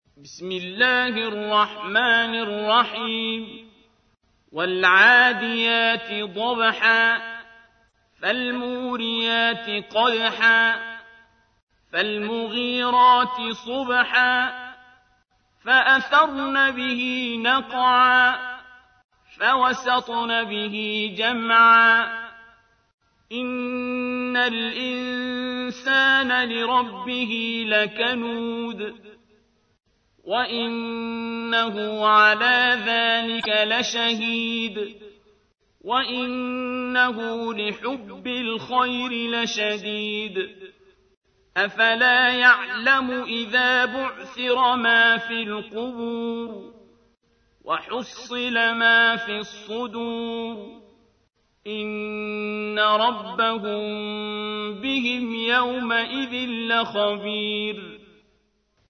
سورة العاديات / القارئ عبد الباسط عبد الصمد / القرآن الكريم / موقع يا حسين